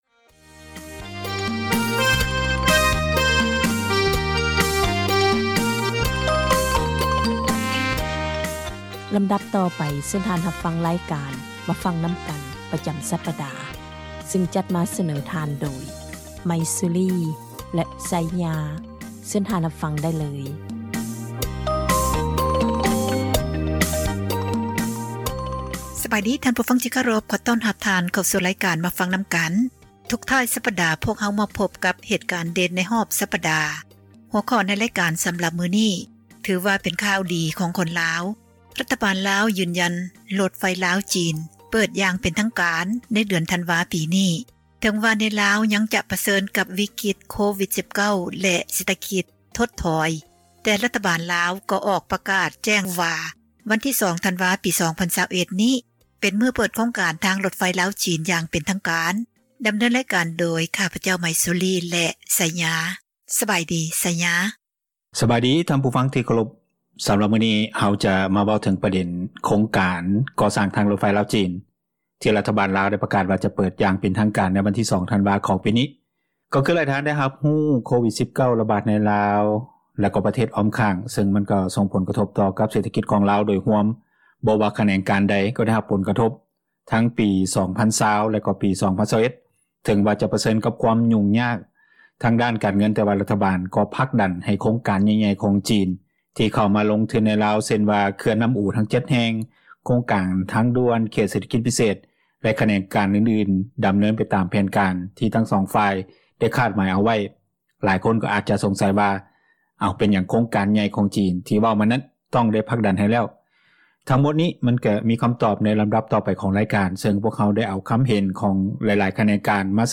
ການສົນທະນາ ໃນບັນຫາ ແລະ ຜົລກະທົບຕ່າງໆ ທີ່ເກີດຂຶ້ນ ຢູ່ປະເທດລາວ.